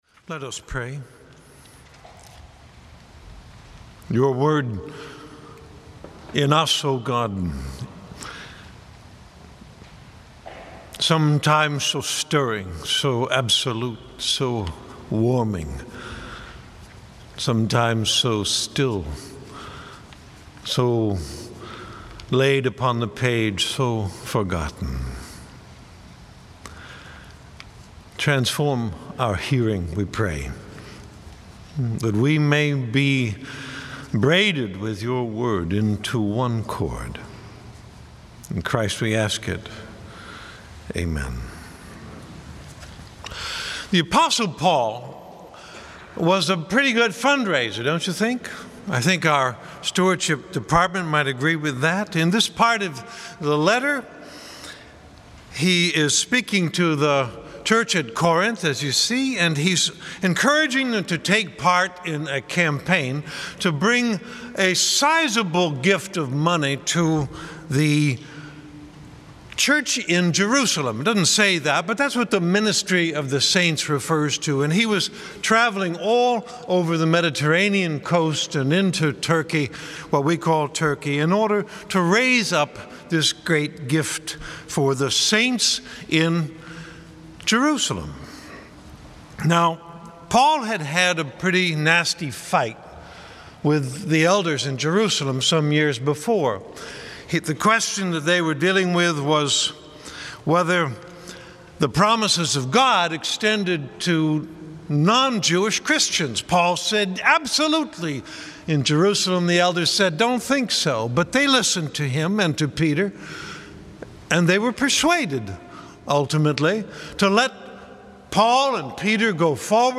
sermon 2013